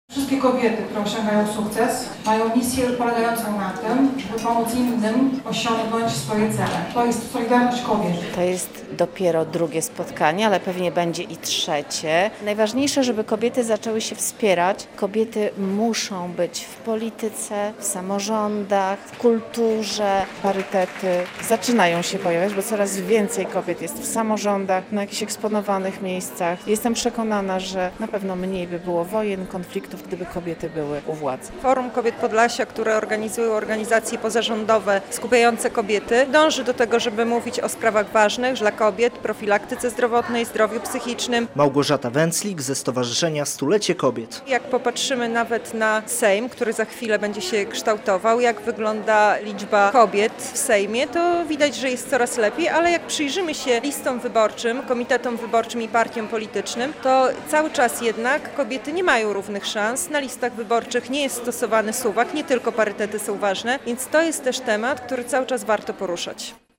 II Forum Kobiet Podlasia - relacja